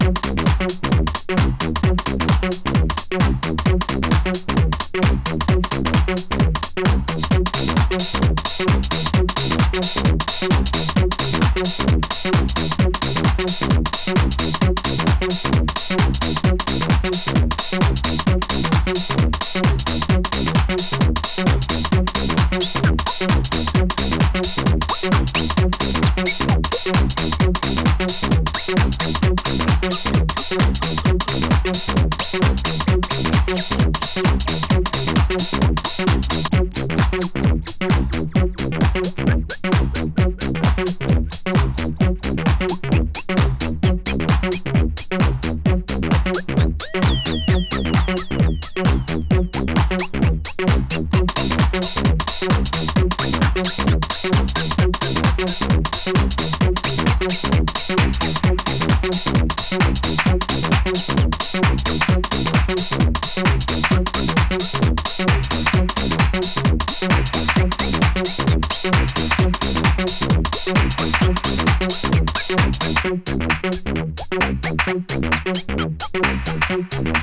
ネバネバしたAcidトラック